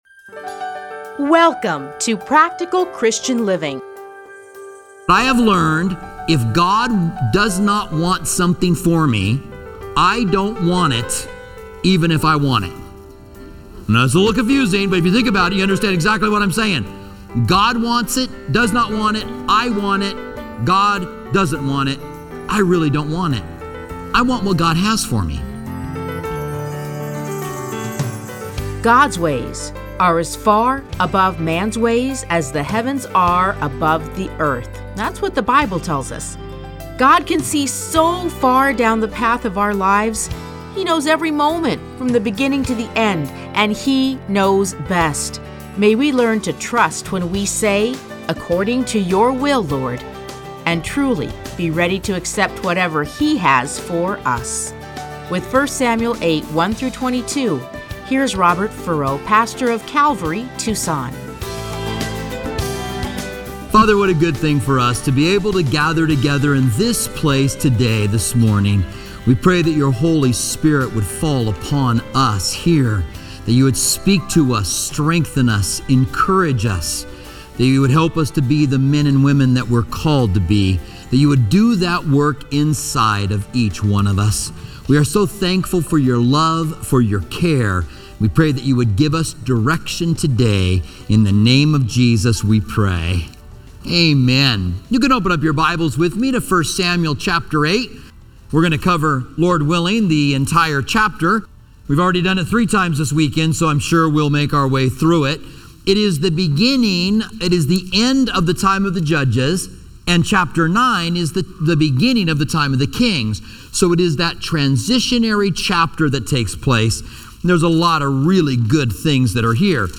teachings are edited into 30-minute radio programs titled Practical Christian Living. Listen to a teaching from 1 Samuel 8:1-22.